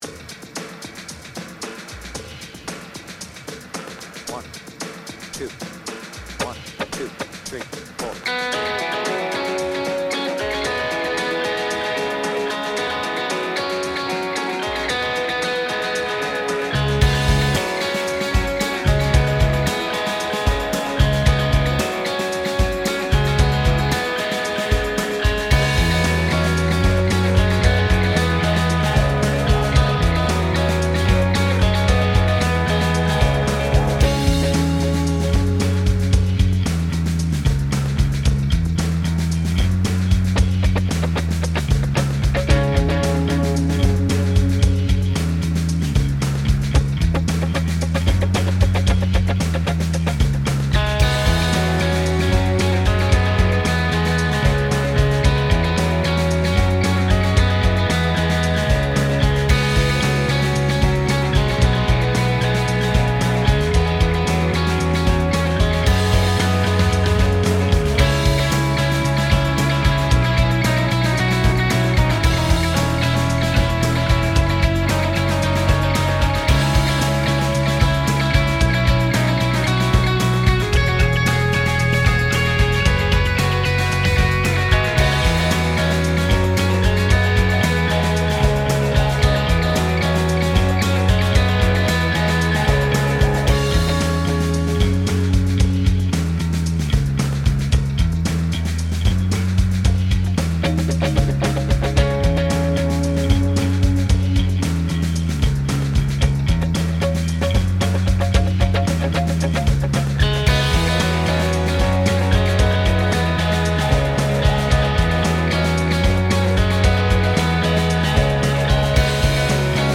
BPM : 113
Tuning : Eb
Without vocals